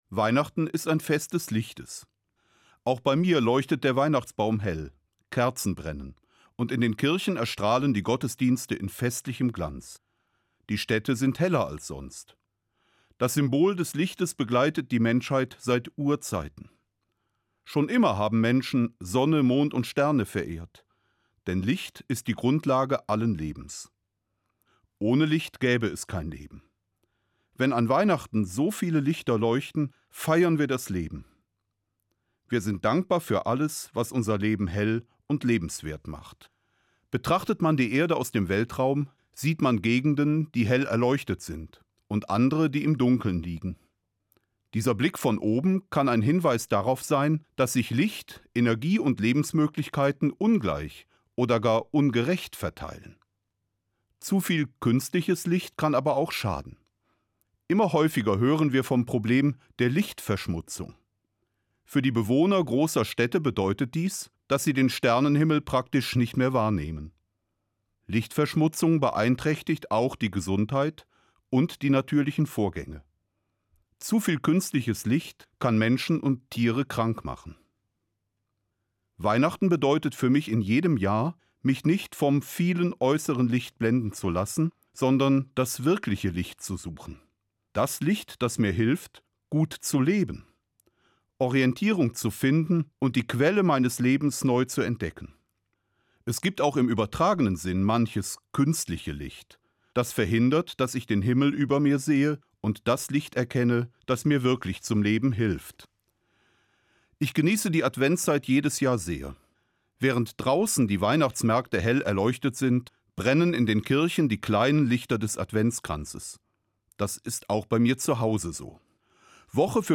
Bischof Peter Kohlgraf spricht in der hr2 Morgenfeier am 1. Weihnachtstag über das Licht von Bethlehem – ein Licht, das nicht blendet, sondern wärmt, tröstet und zum Frieden ruft.
Eine Sendung von Peter Kohlgraf, Bischof von Mainz